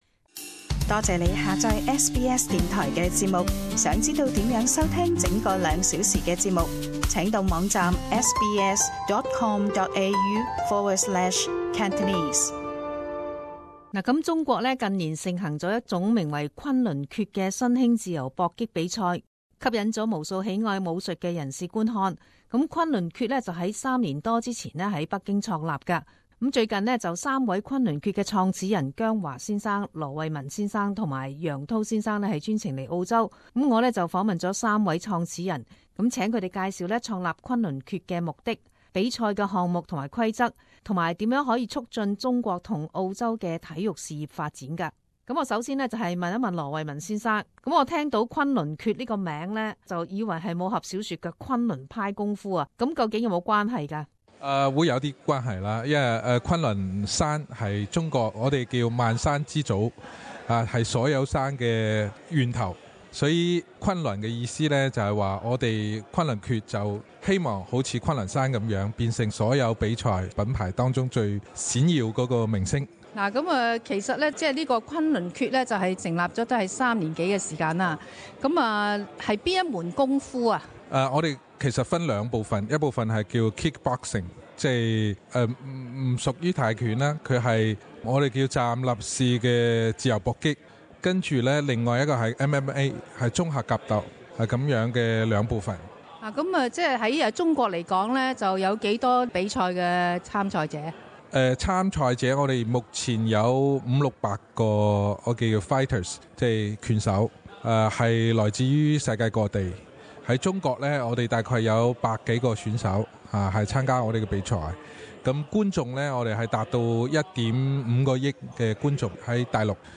Community interview- Kunlun Fight